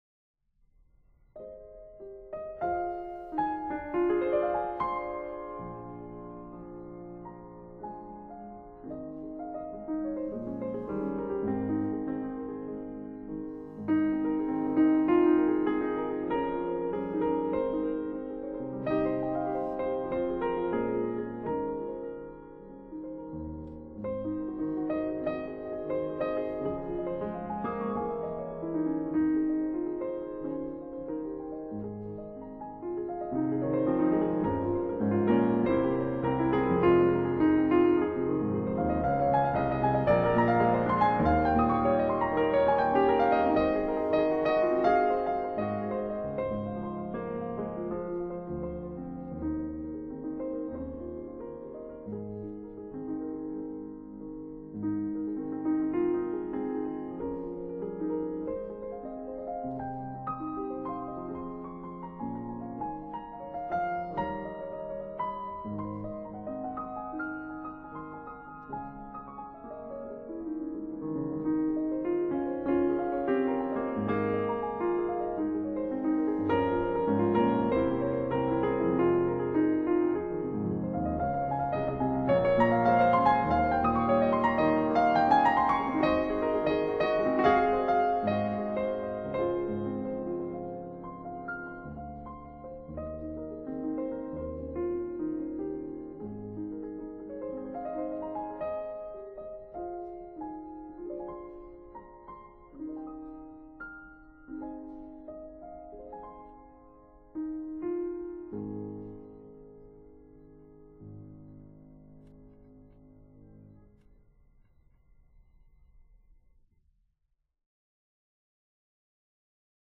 Genre: Instruments, Piano, Classical